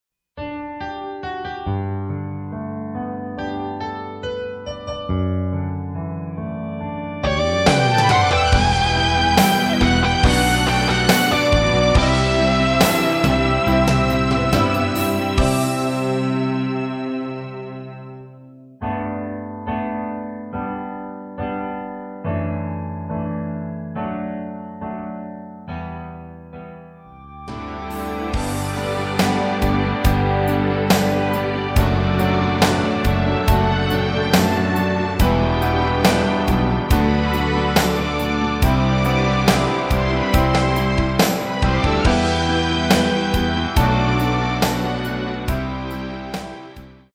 MR입니다.